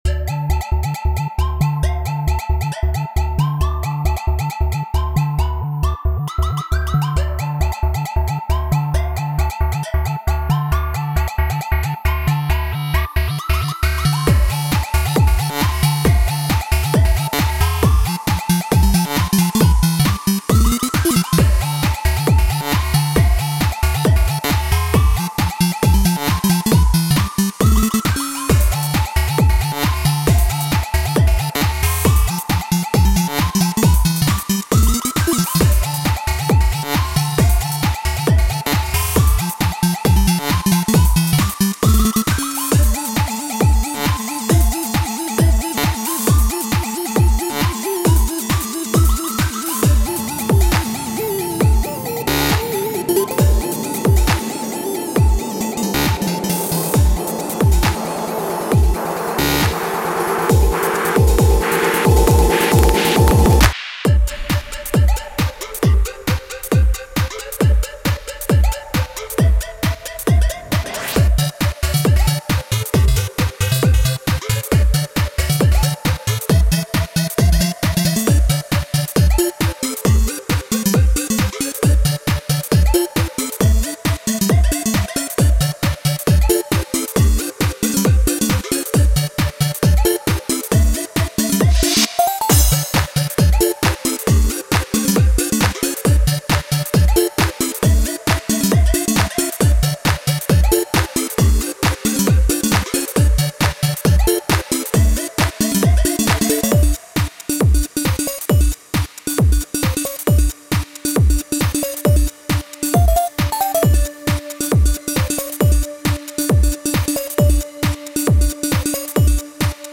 A progressive house track with so much color, I had to give it the title “Cosby Sweater”.